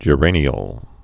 (jə-rānē-əl)